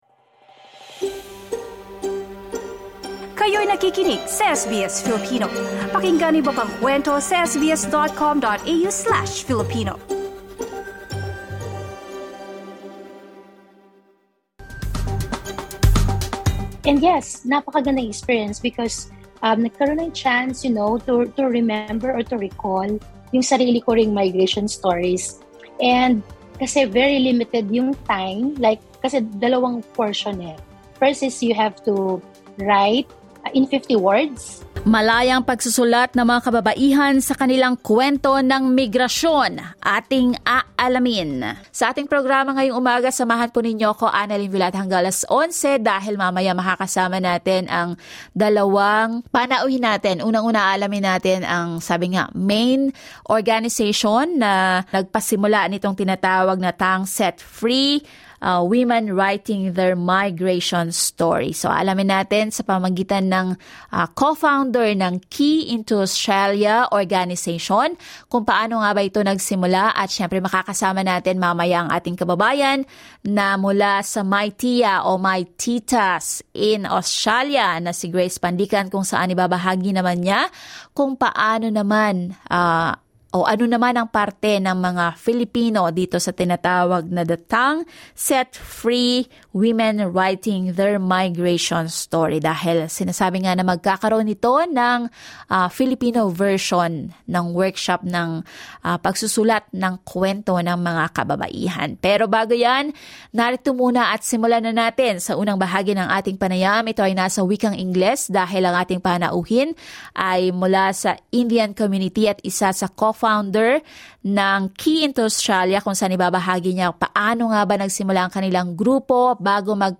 Family is a common theme for every Filipino migrant story. LISTEN TO THE INTERVIEW